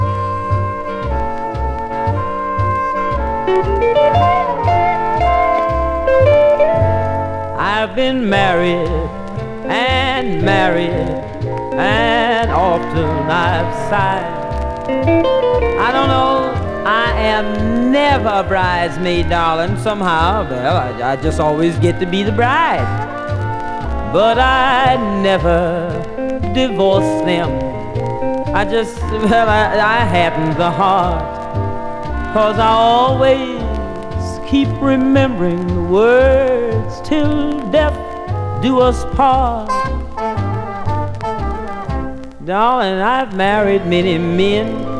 演技同様にさまざまな歌い分け、バックバンドの演奏も冴えてます。
Jazz, Pop, Vocal　USA　12inchレコード　33rpm　Mono